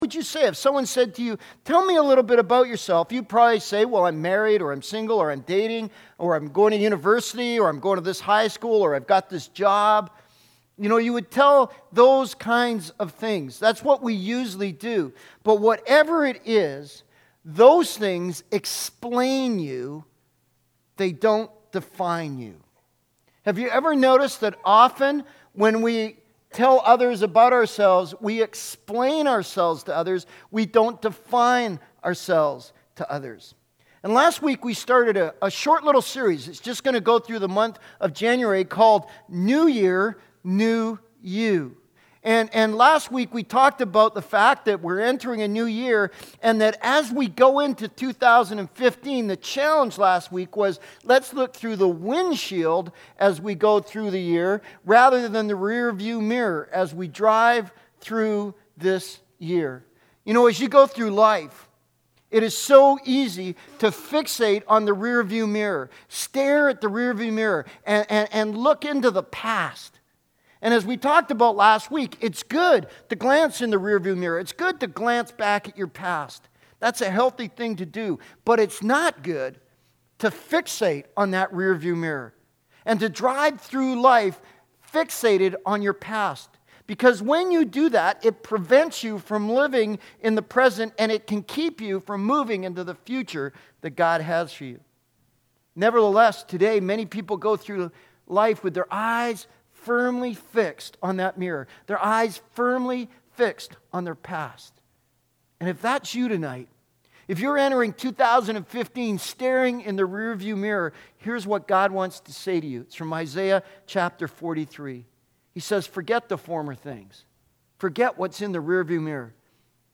New Year, New You Sermon Series: Ephesians 1: 1-2